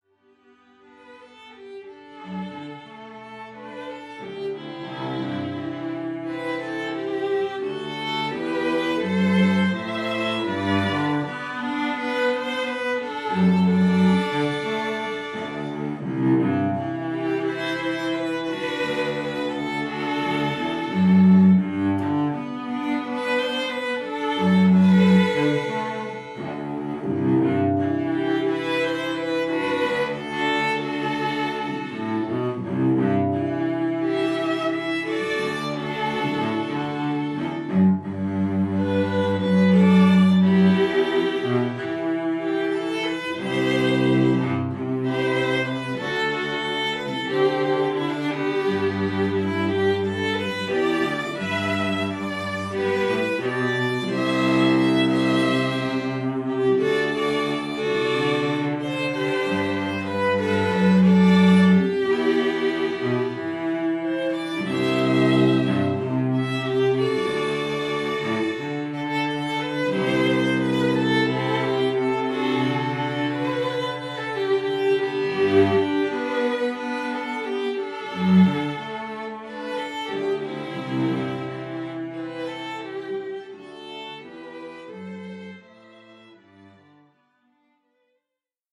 Trio: Violin, Viola, & Cello – Contemporary